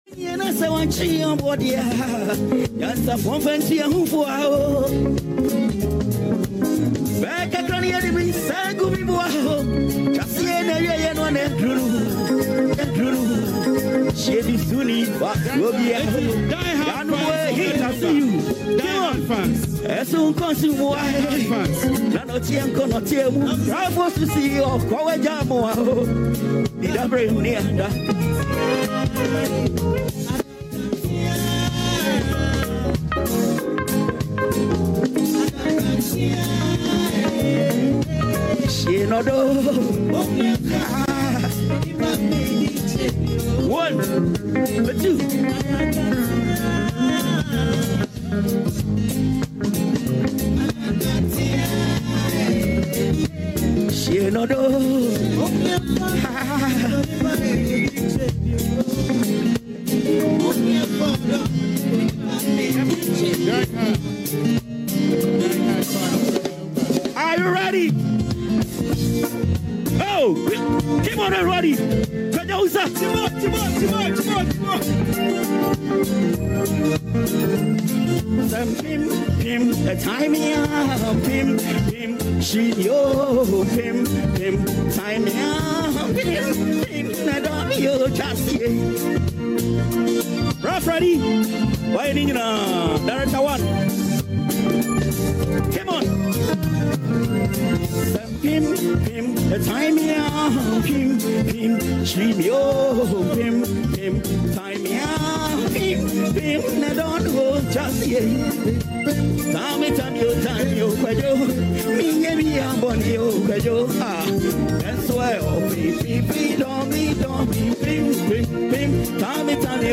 one-week observation at BlackStar square